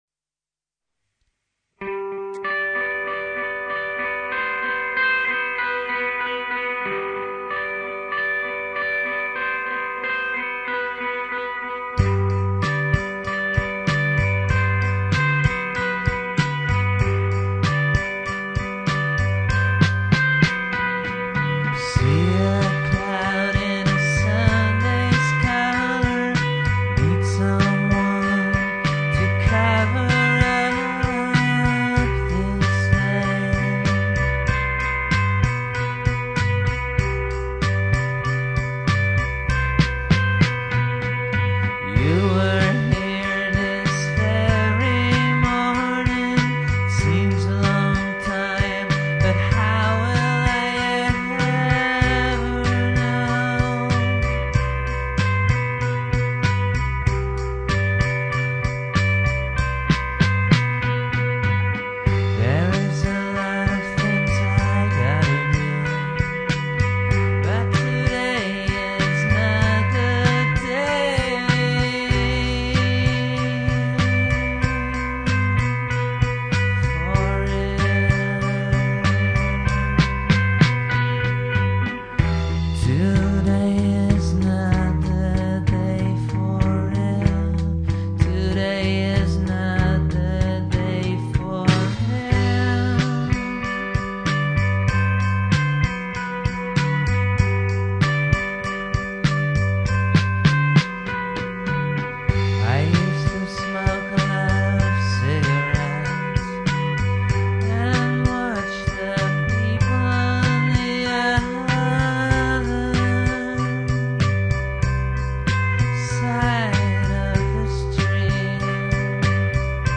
where: recorded at CMA (Amsterdam)